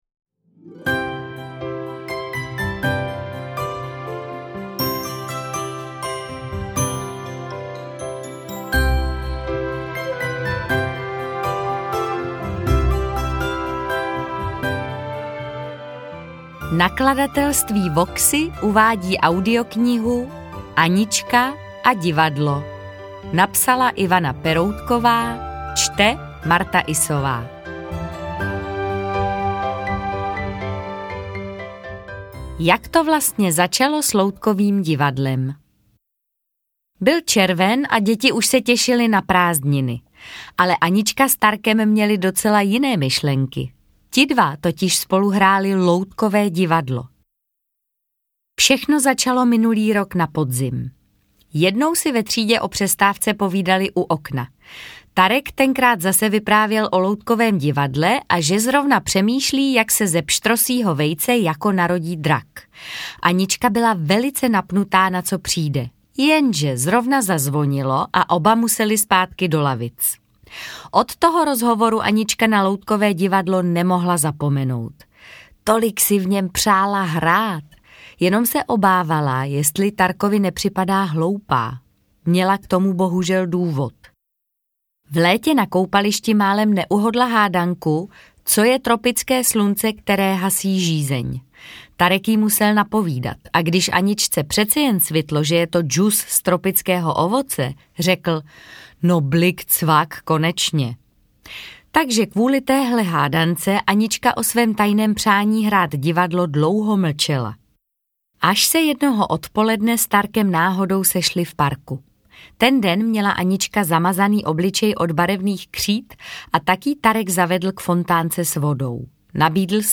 Interpret:  Martha Issová